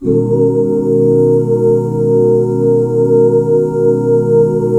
BMAJ7 OOO -L.wav